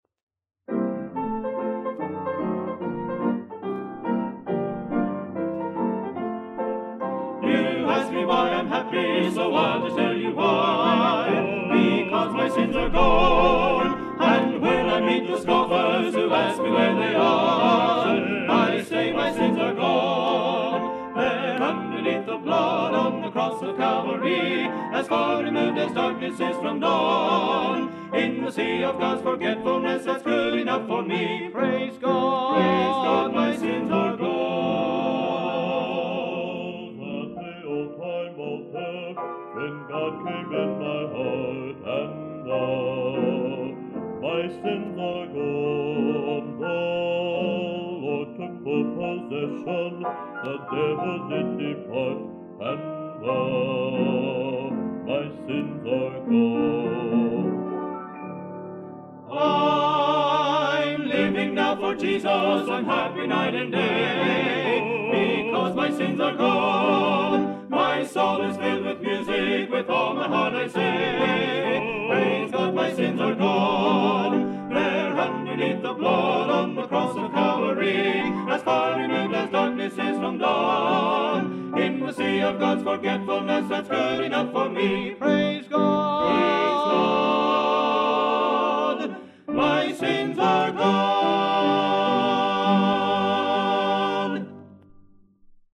vocal members